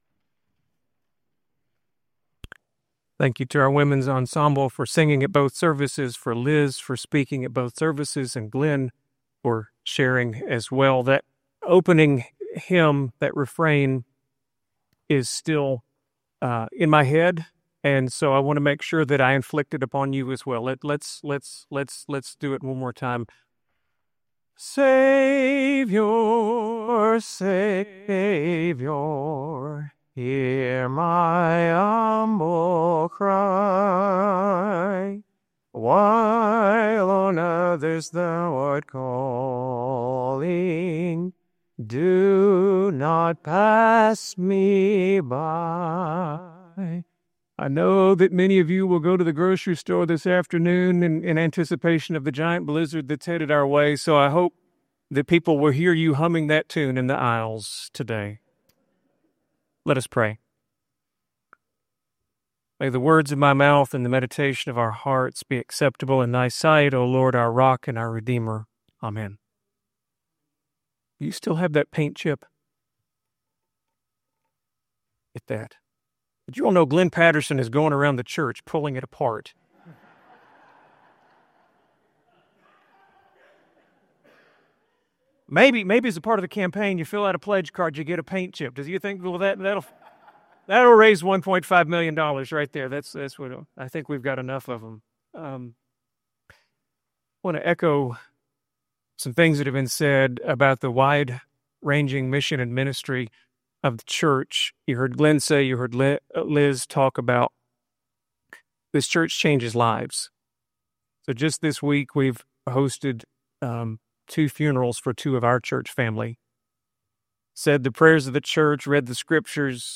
Watch the full sermon in the video above, or click the play button below to listen to the message as a podcast.